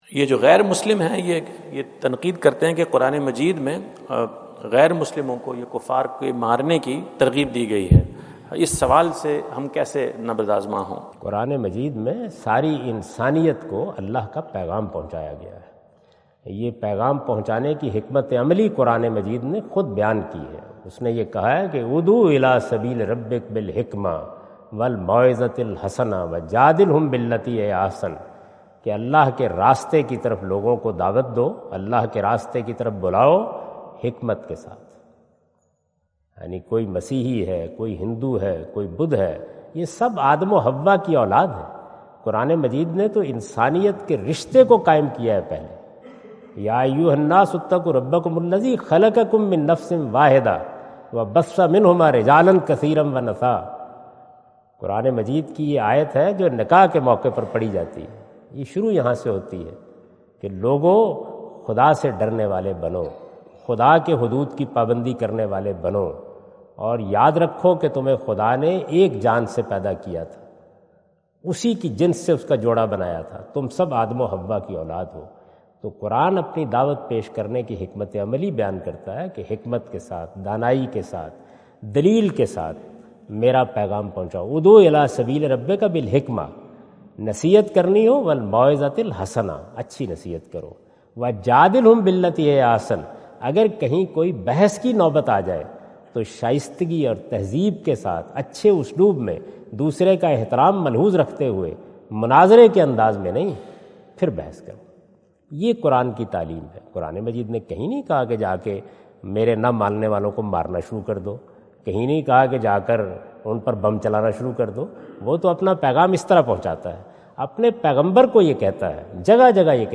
Javed Ahmad Ghamidi answer the question about "How to deal with a question that Quran promotes violation because it provoke to kill non-Muslims?" During his US visit at Wentz Concert Hall, Chicago on September 23,2017.